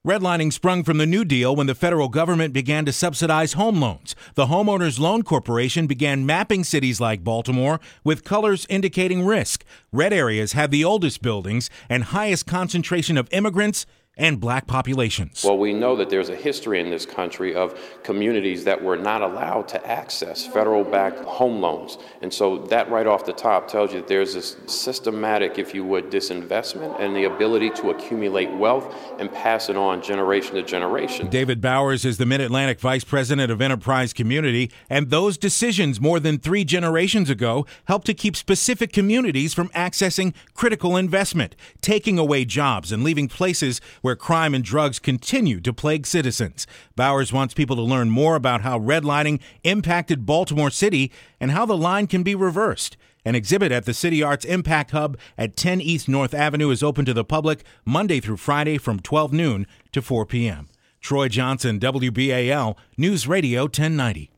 A story that I filed for WBAL NewsRadio 1090, in June of 2017. A new exhibit explores the impact of redlining in the city of Baltimore. The practice implemented by the Federal Government in the 1930's, has links to problems that persist today.